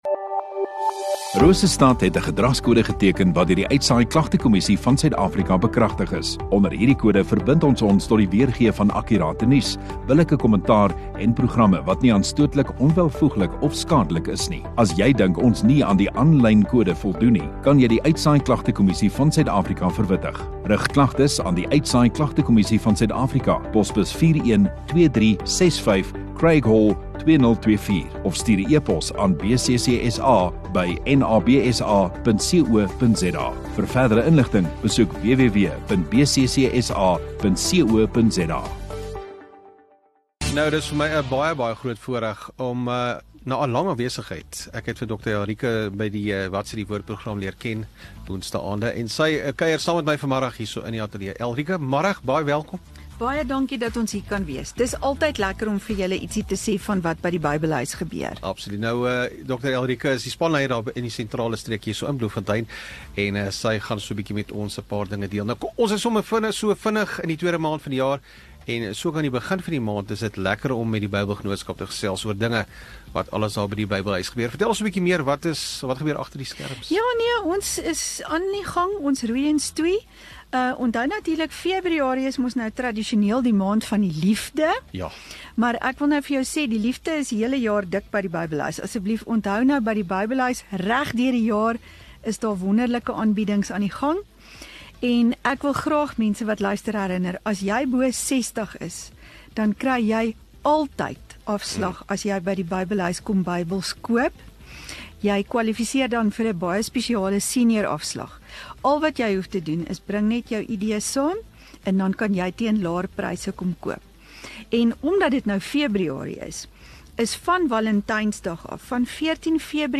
Radio Rosestad View Promo Continue Radio Rosestad Install Gemeenskap Onderhoude 4 Feb Bybelgenootskap